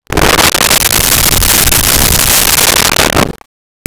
Creature Breath 02
Creature Breath 02.wav